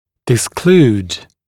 [dɪs’kluːd][дис’клу:д]разобщать (зубы), выводить из прикуса